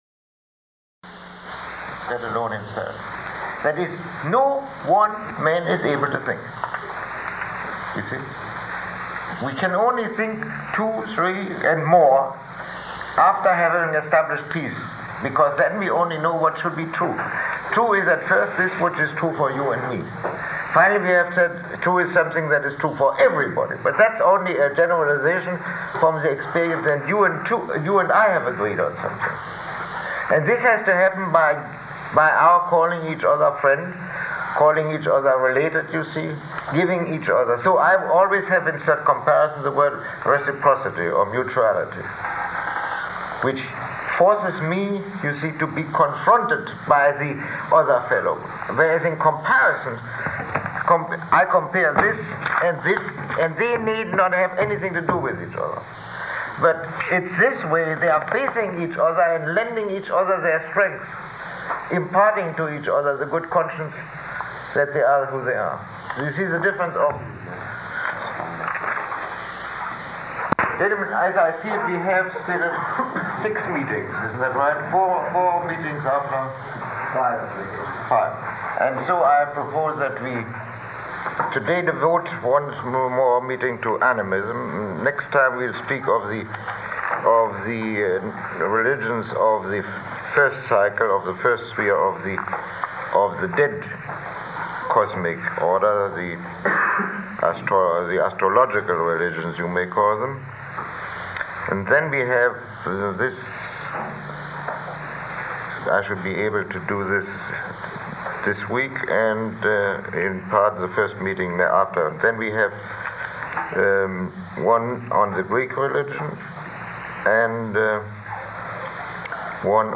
Lecture 23